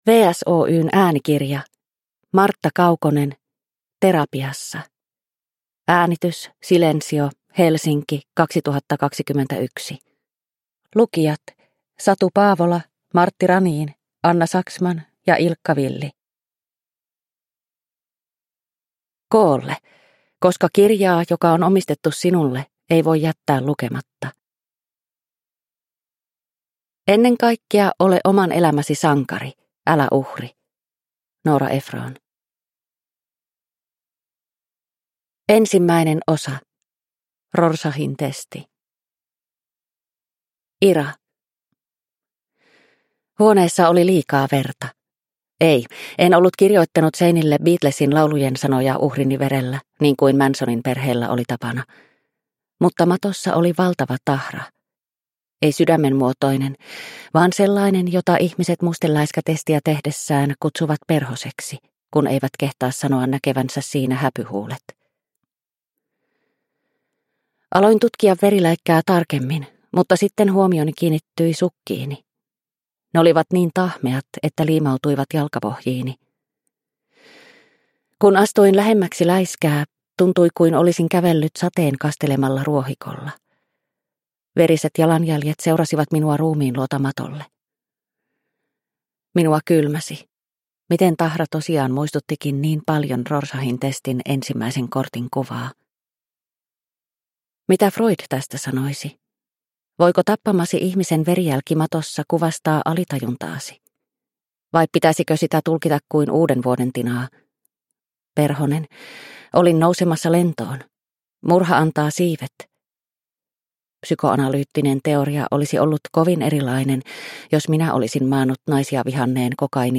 Terapiassa – Ljudbok – Laddas ner